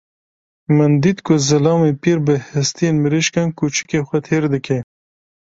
Uitgesproken als (IPA)
/piːɾ/